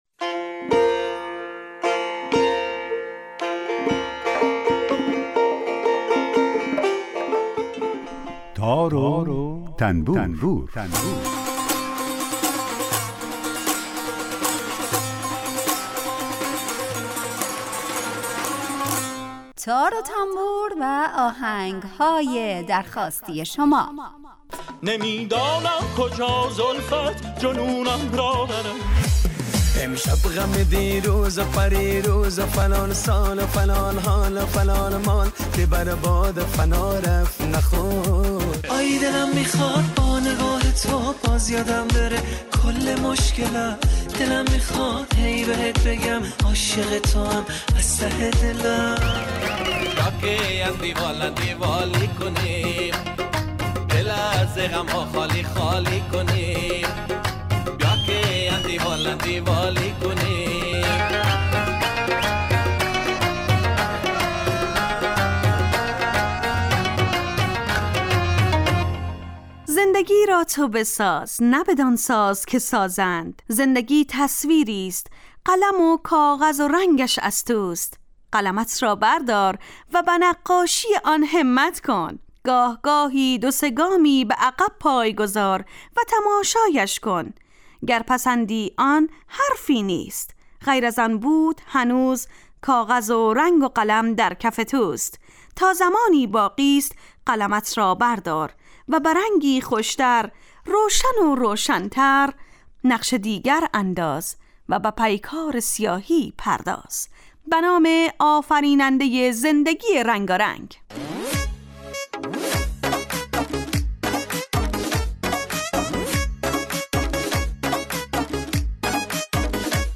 آهنگ های درخواستی